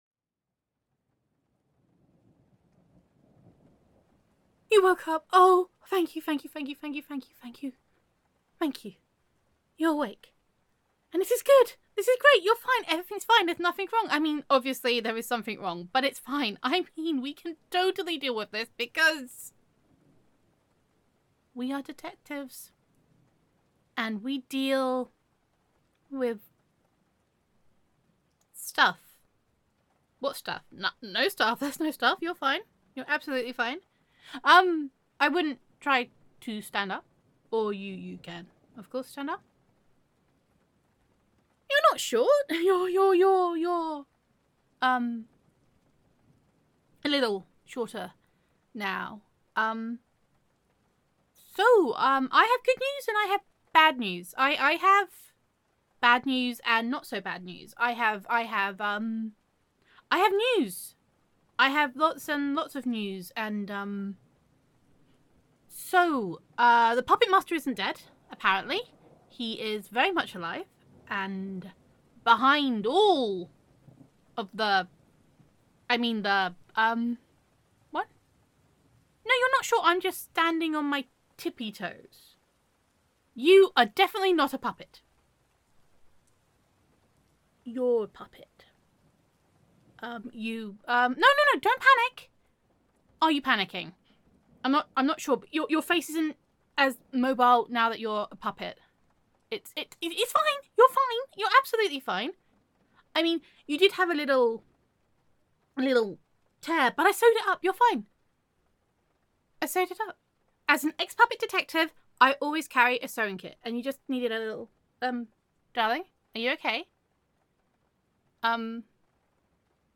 This new microphone is very bright and editing it was an experience.
[F4A]